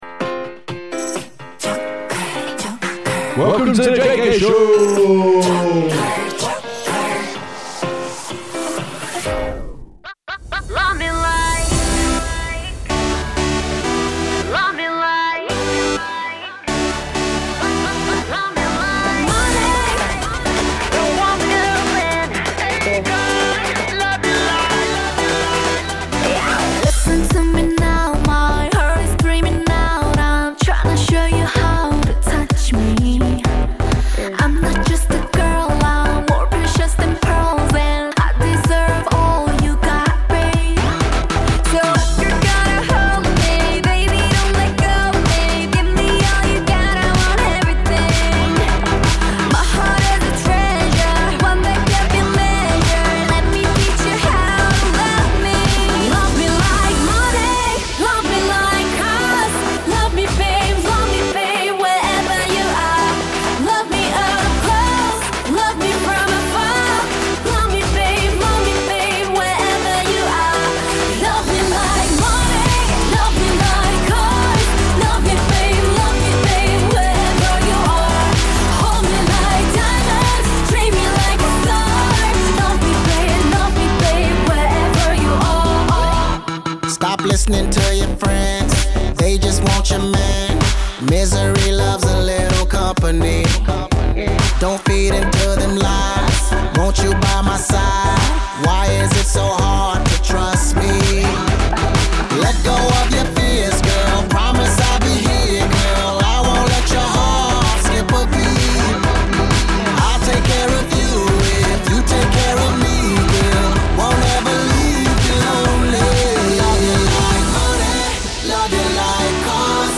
Jpop, J-Metal, Kpop, K-Rock and even K-Hip Hop.